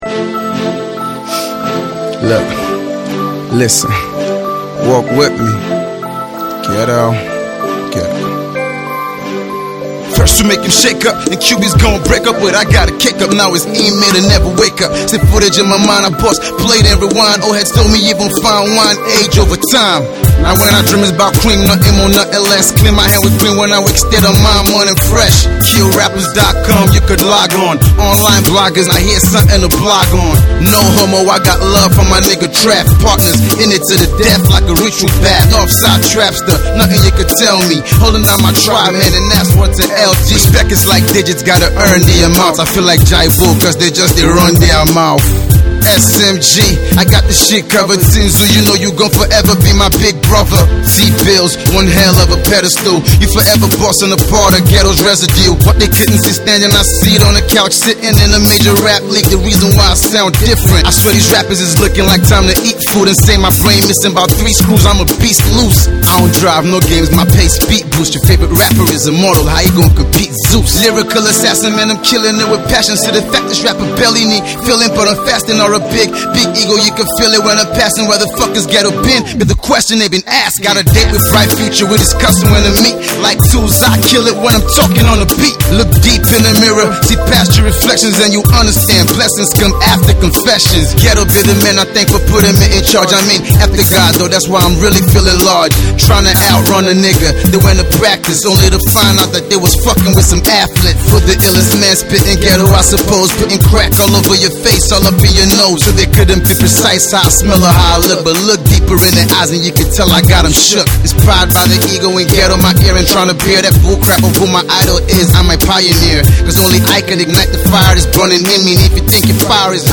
rappers
he’s back with heavy hardcore Hip-Hop track